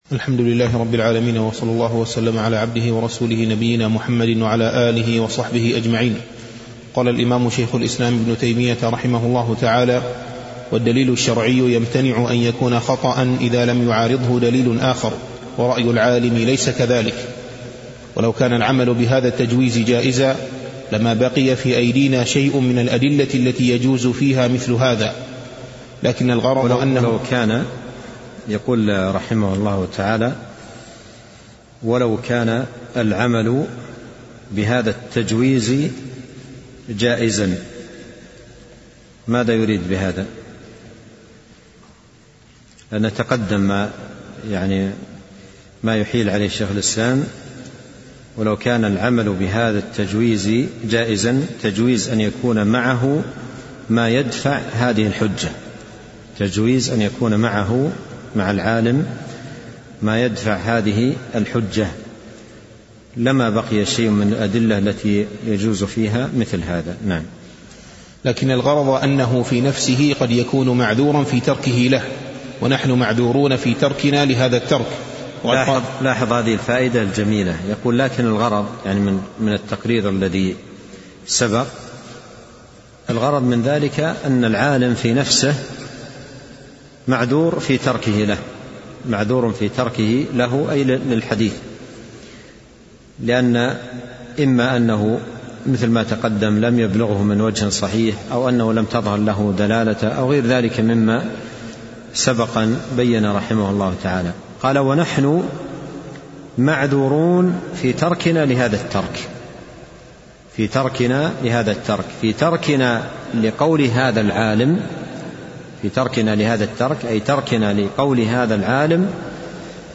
محاضرتان صوتيتان